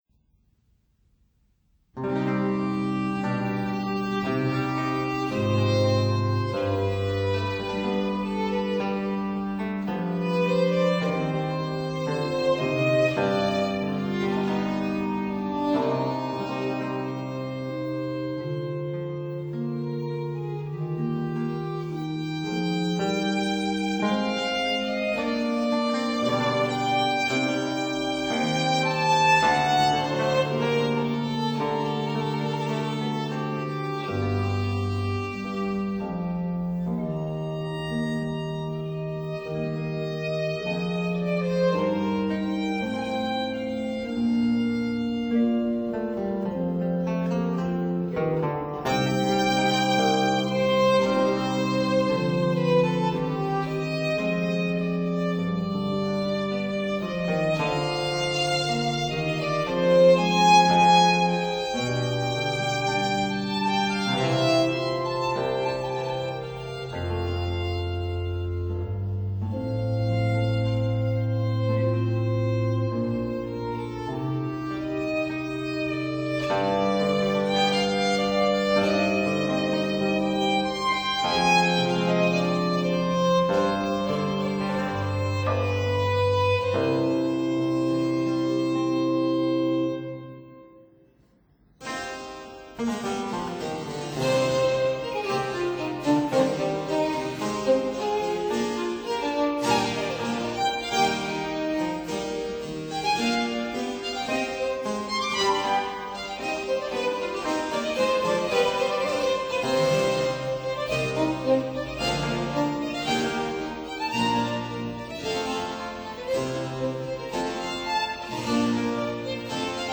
Lute & Theorbo
Harpsichord & Organ
(Period Instruments)